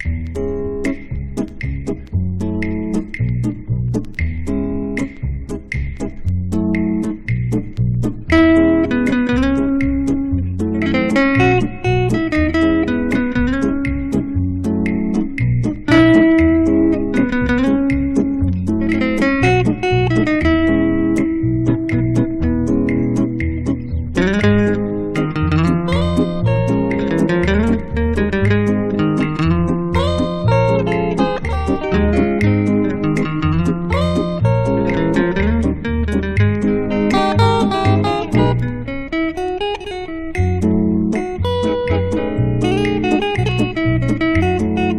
Pop, Jazz, Easy Listening　Germany　12inchレコード　33rpm　Stereo